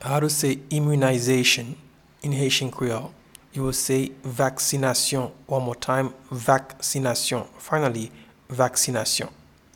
Pronunciation and Transcript:
Immunization-in-Haitian-Creole-Vaksinasyon.mp3